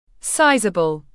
Sizable /ˈsaɪ.zə.bəl/